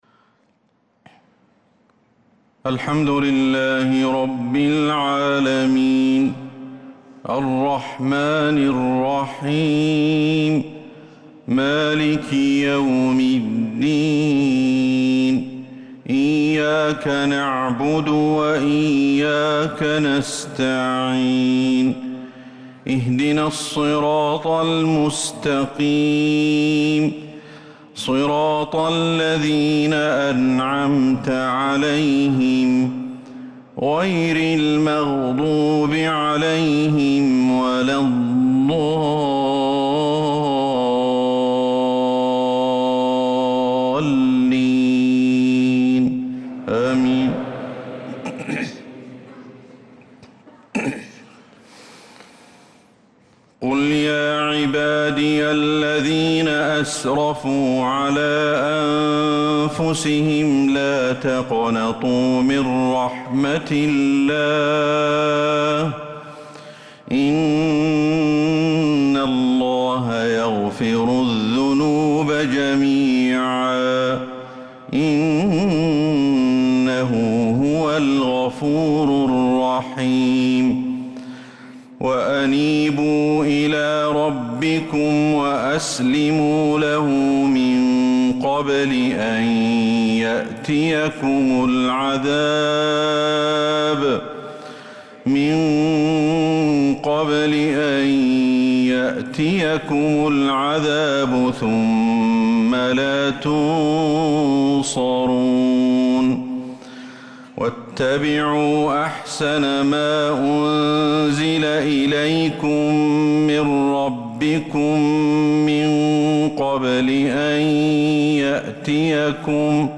عشاء الأحد 5-7-1446هـ من سورة الزمر 53-66 | ishaa prayer from surah Az-Zumer 5-1-2025 > 1446 🕌 > الفروض - تلاوات الحرمين